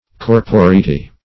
Corporeity \Cor`po*re"i*ty\ (k?r`p?-r?"?-t?), n. [LL.